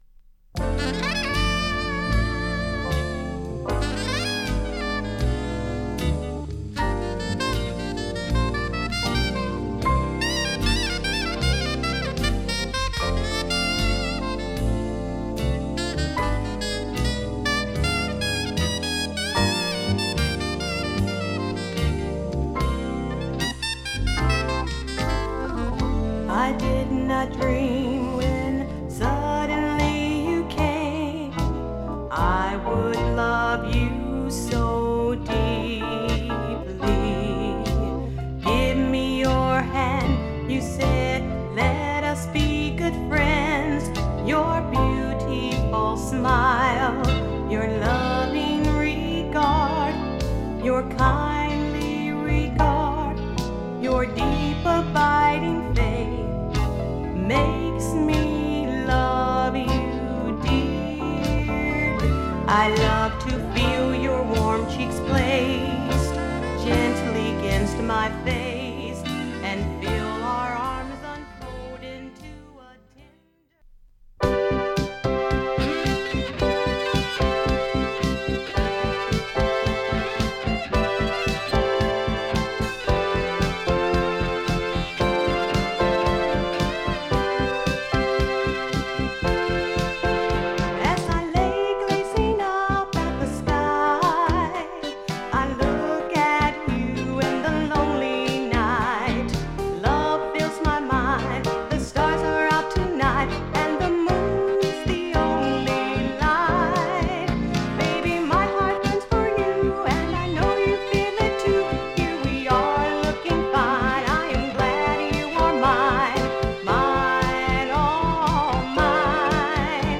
サウダージを感じる黄昏ヨット・ロック